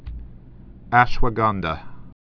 (ăshwə-gändə, äsh-)